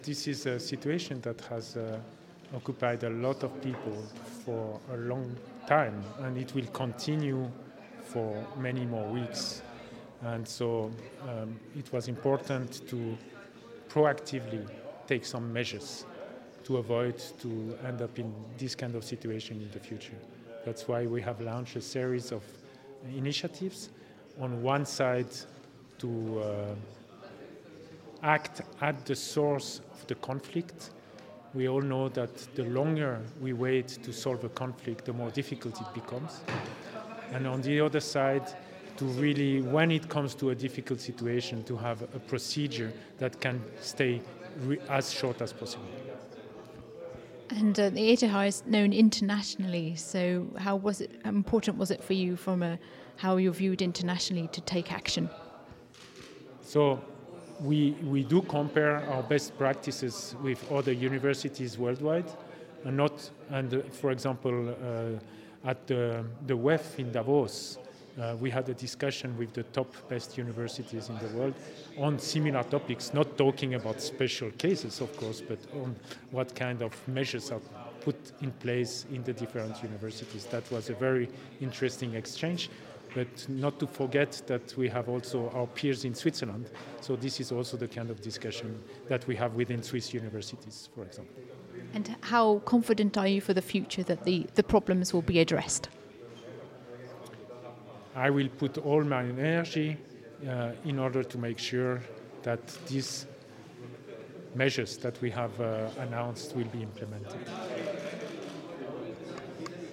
woman asking man questions